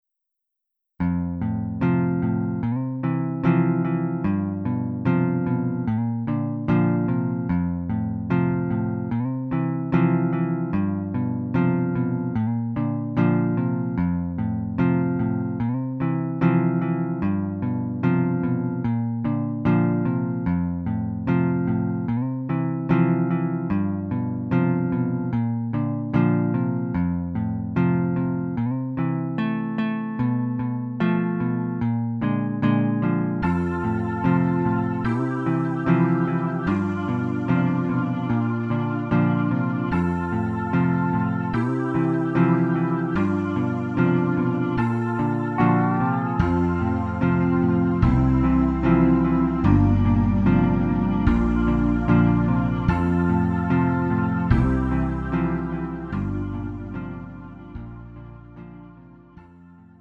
음정 -1키 2:33
장르 가요 구분 Lite MR